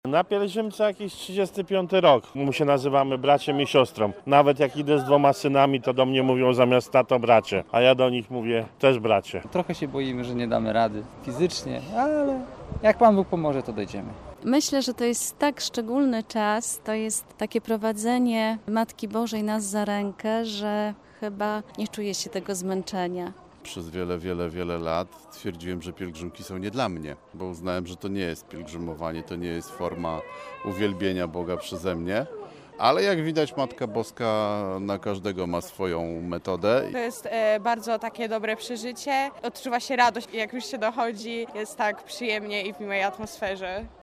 Po Eucharystii pielgrzymi wyruszyli podzieleni na trzy grupy. Wielu z nich ma już doświadczenie w wędrowaniu do Częstochowy, nie brakowało jednak debiutantów: